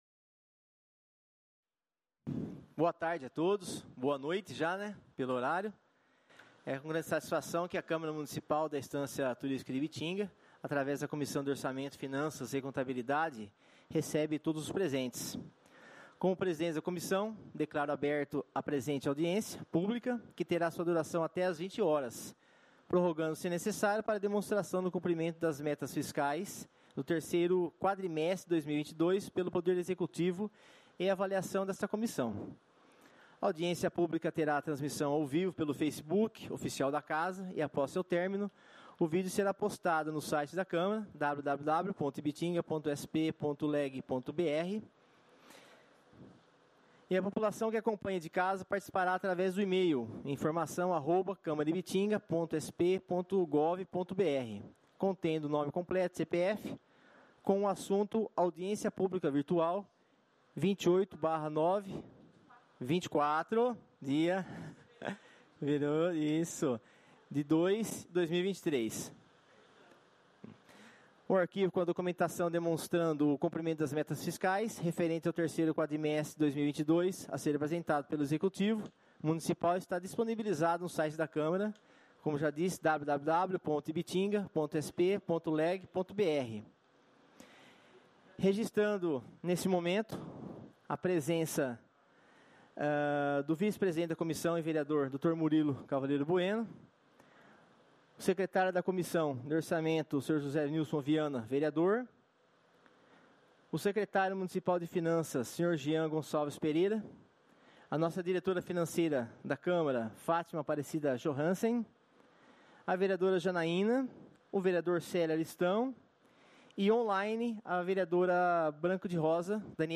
Audiências Públicas